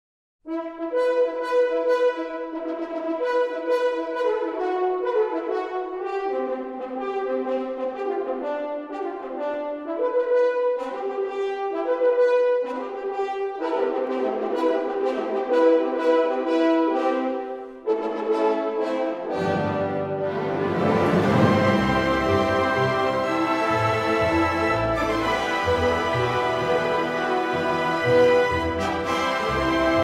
Suite